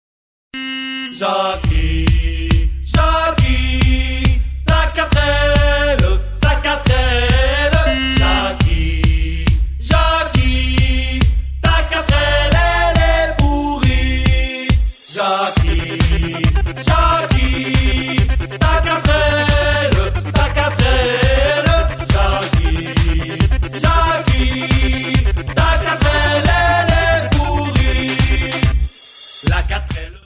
J adore la fin avec les bruits de toles qui se froissent lol